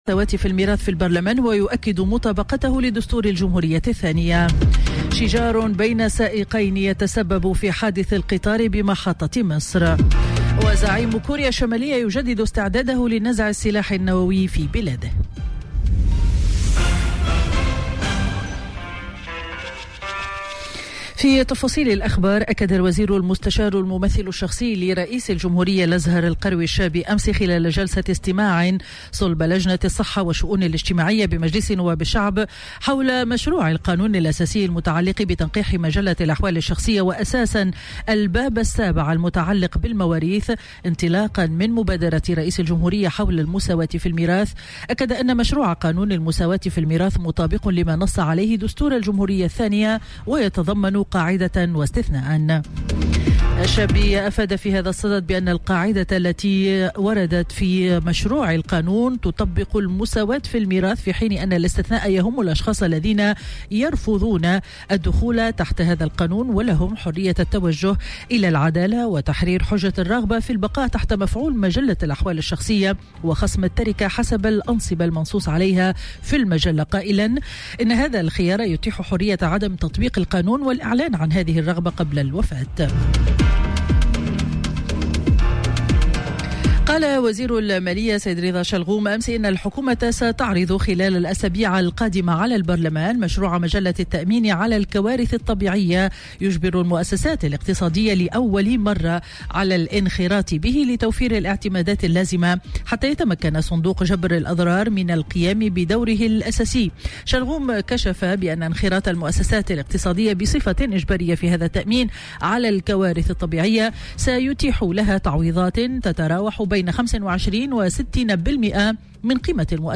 Journal Info 07h00 du jeudi 28 février 2019